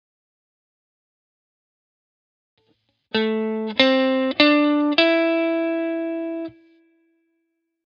Listen here to 4 random notes of the A minor pentatonic scale